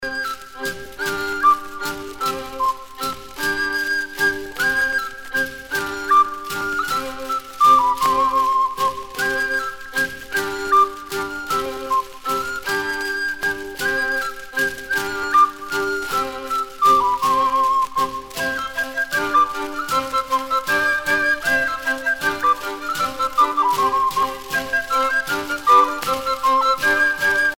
circonstance : carnaval, mardi-gras ;
Pièce musicale éditée